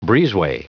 Prononciation du mot breezeway en anglais (fichier audio)
Prononciation du mot : breezeway